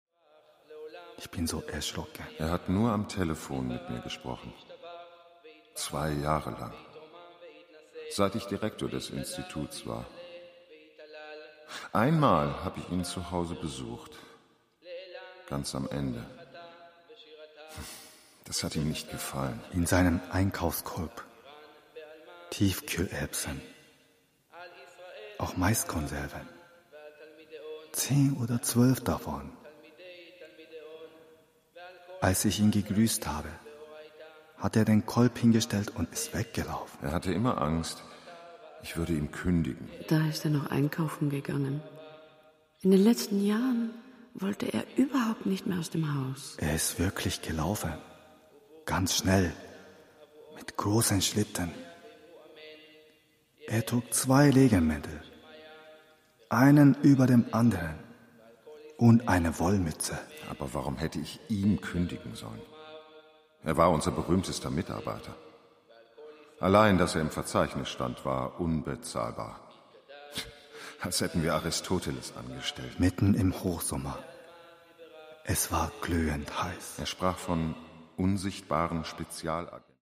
Fassung: Hörspiel